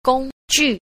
10. 工具 – gōngjù – công cụ